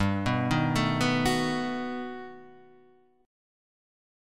G+M9 chord {3 6 4 4 4 5} chord